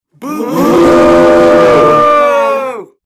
Crowd Booing
Crowd_booing.mp3